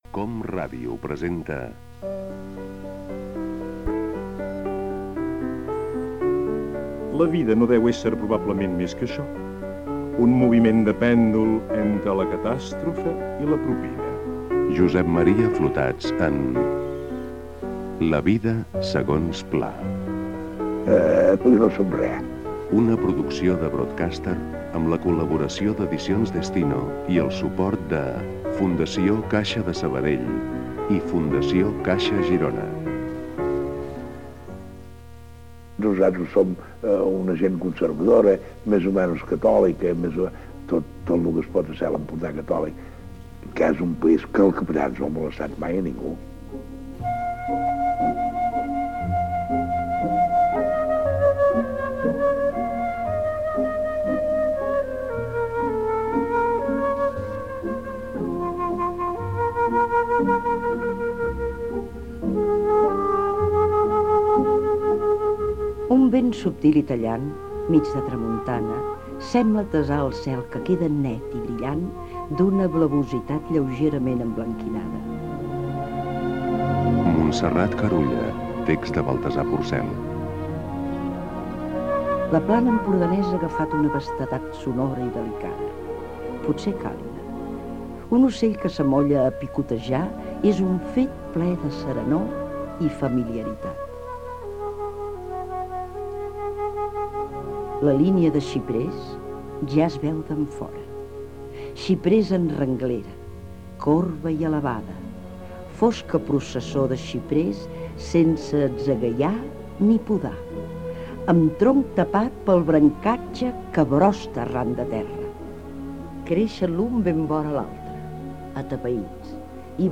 Especial emès amb motiu del centenari del naixement de l'escriptor Josep Pla. Careta del programa. Paraules de Josep Pla, lectura de fragments de la seva obra, opinions de l'escriptor. Hi intervé l'escriptor Baltasar Porcel,
Cultura